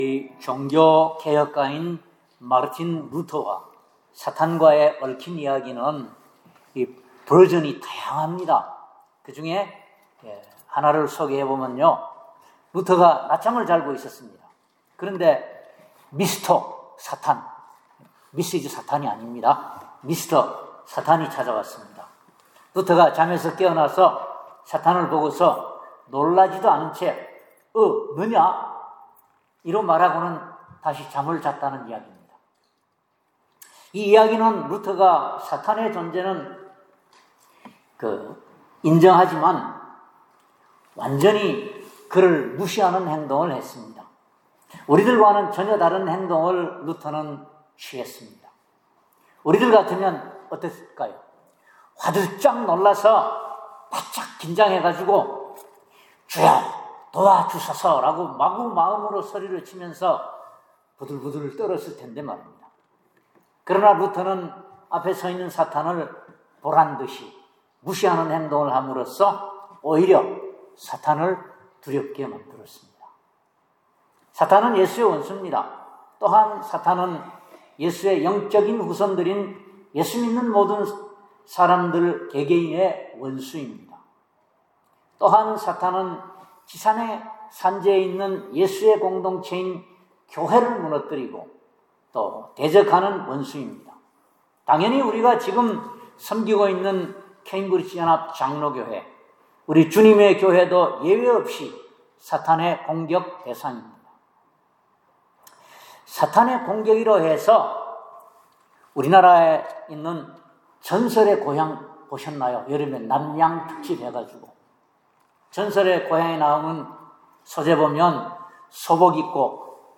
슥 1:17-21 Service Type: 주일예배 사탄은 예수의 원수입니다.